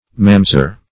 Search Result for " mamzer" : The Collaborative International Dictionary of English v.0.48: Mamzer \Mam"zer\, n. [Heb. m['a]mz[=e]r.]